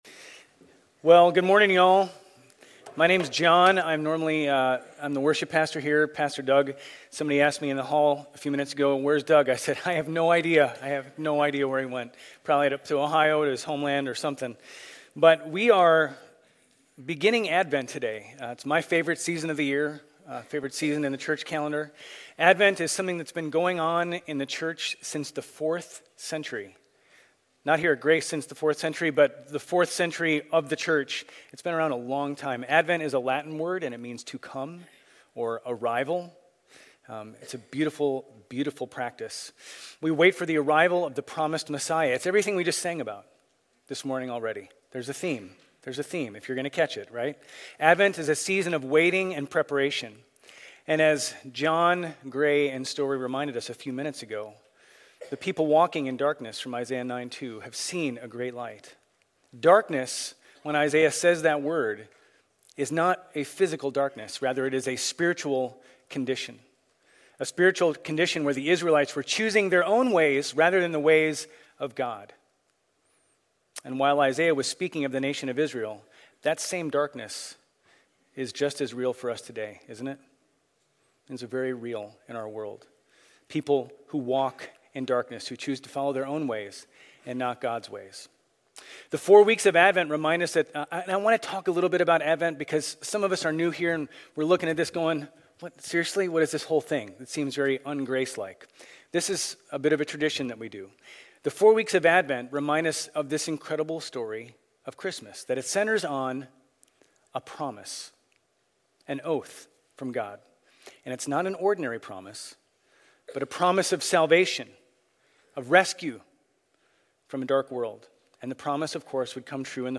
Grace Community Church Old Jacksonville Campus Sermons Advent: Luke 1:67-79 Nov 26 2023 | 00:38:51 1x 00:00 / 00:38:51 Subscribe Share RSS Feed Share Link Embed